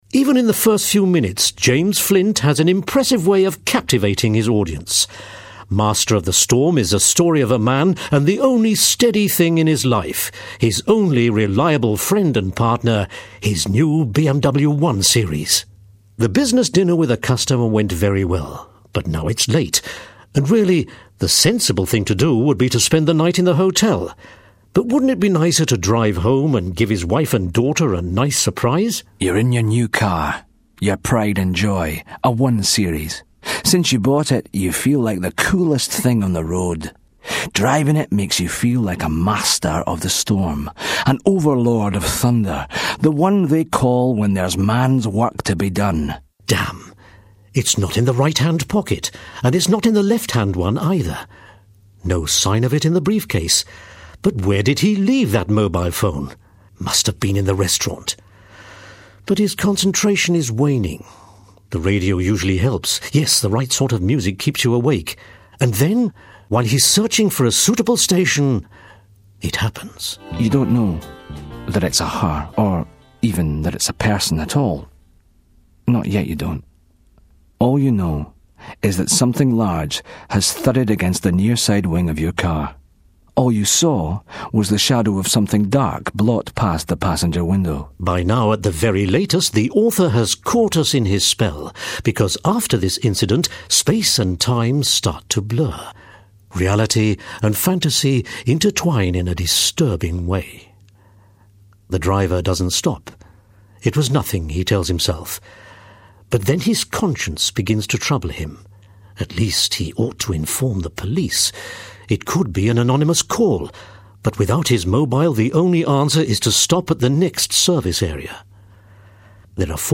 BMW Audio Book. "Master of the Storm" - James Flint (English Version).
Profile: 128 KBit/s, 44100 Hz, Stereo